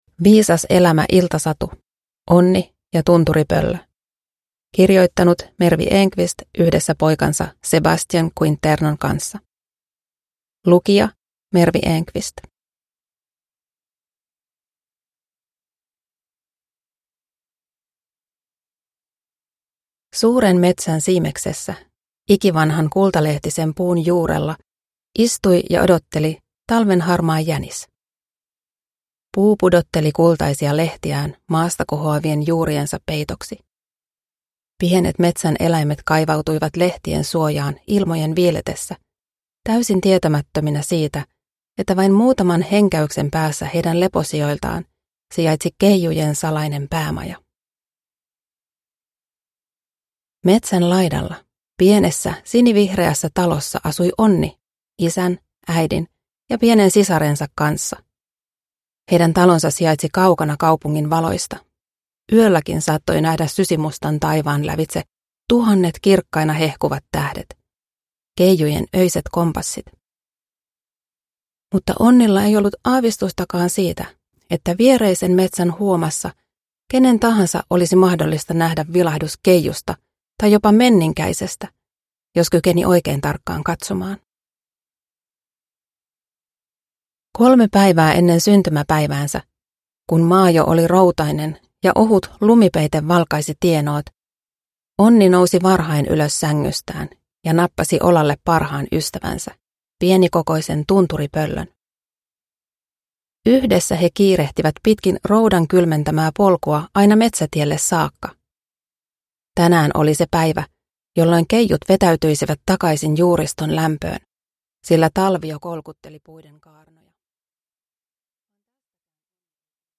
Onni ja tunturipöllö -iltasatu – Ljudbok